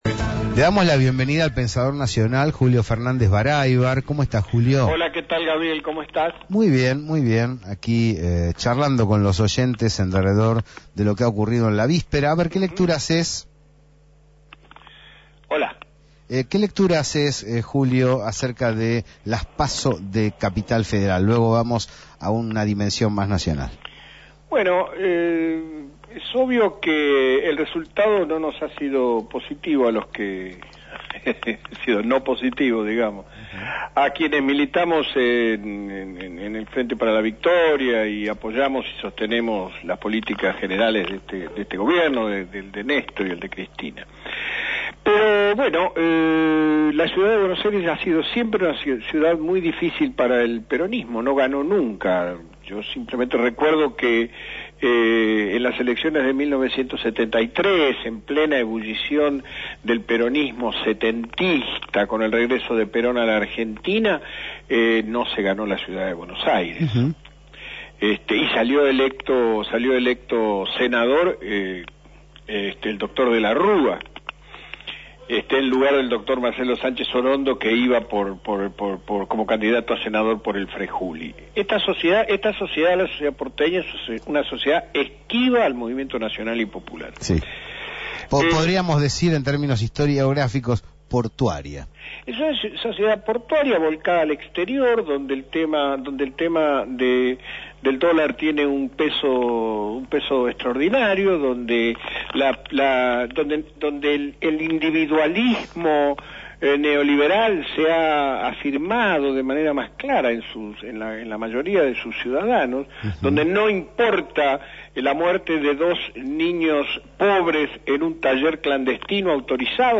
El análisis post-electoral de las PASO porteñas estuvo presente en Radio Gráfica.